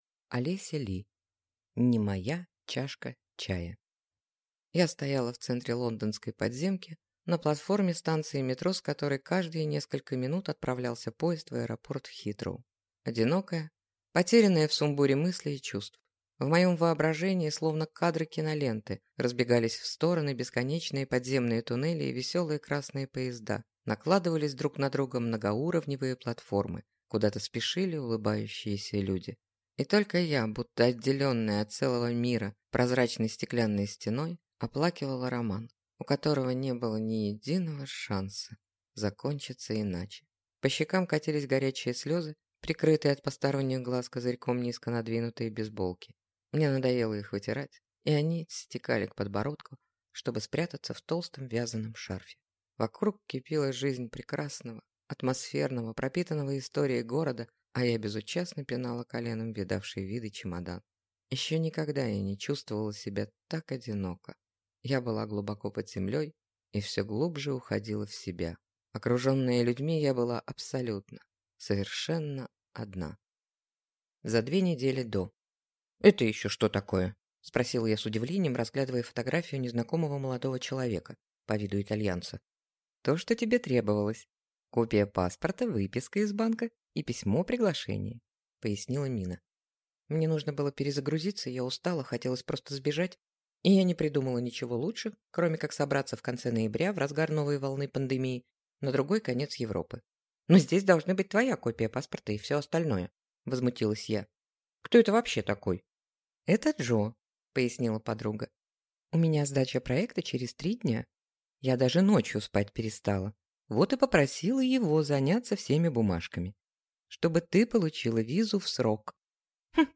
Аудиокнига Не моя чашка чая | Библиотека аудиокниг